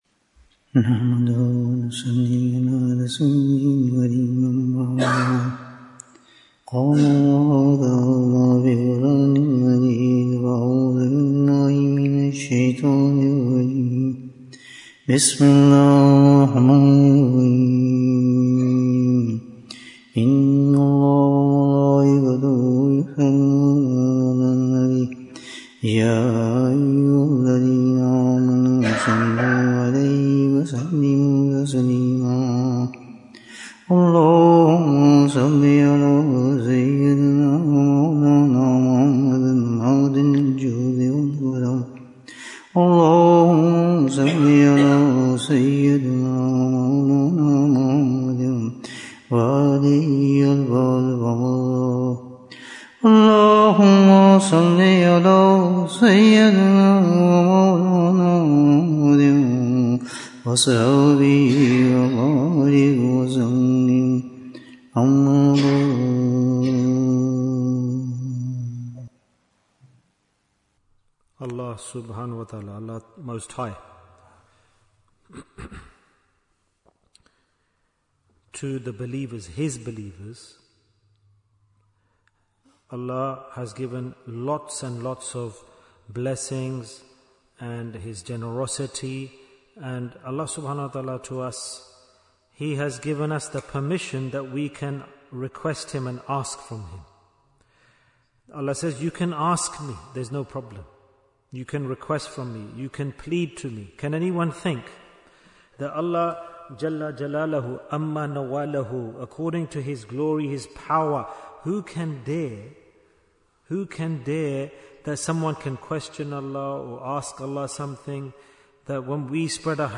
Who Will go to Jannah Without Reckoning? Bayan, 43 minutes8th May, 2025